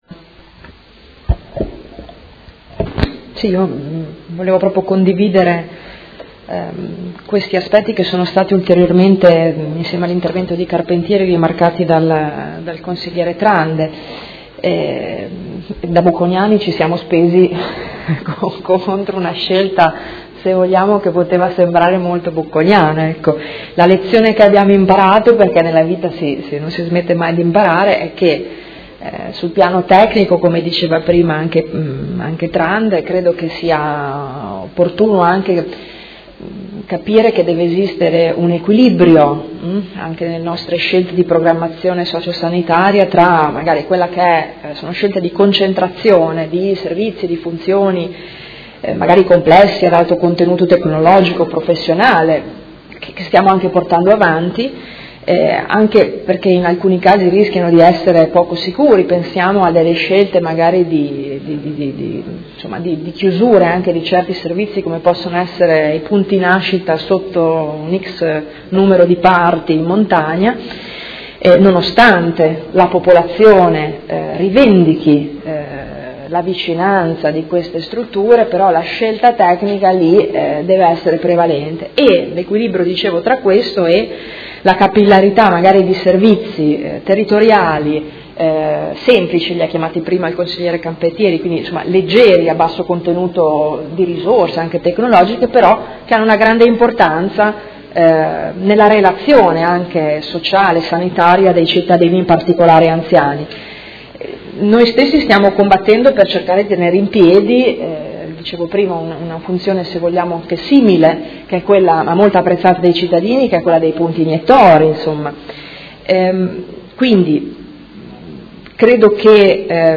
Seduta dell'11/05/2017 Conclusioni. Mozione presentata dal Gruppo Art.1-MDP avente per oggetto: Garantire la continuità del Centro Prelievi di Modena Est